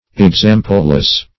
Exampleless \Ex*am"ple*less\, a. Without or above example.